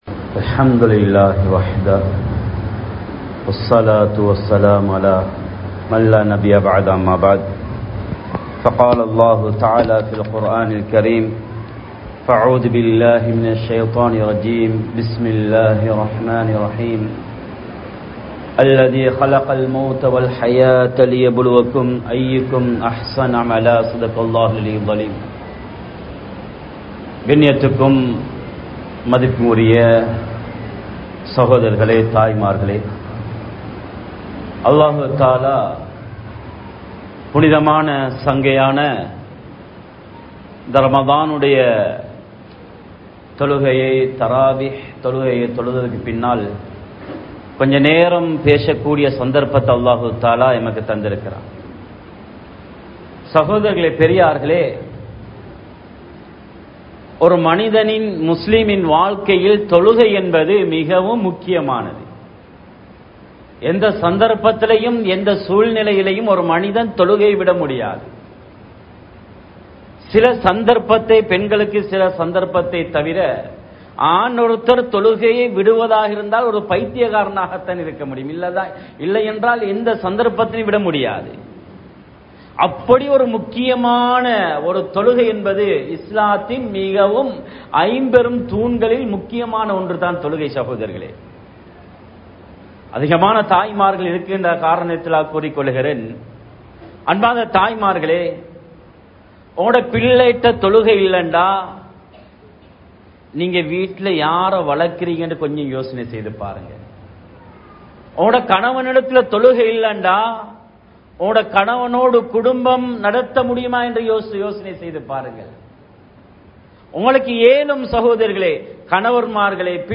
ஏற்றுக்கொள்ளப்படும் நோன்பு | Audio Bayans | All Ceylon Muslim Youth Community | Addalaichenai